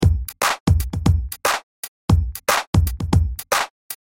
Hip Hop Drum Loop 116 bpm
Description: Hip Hop drum loop 116 bpm.
Genres: Drum Loops
Tempo: 116 bpm
Hip-hop-drum-loop-116-bpm.mp3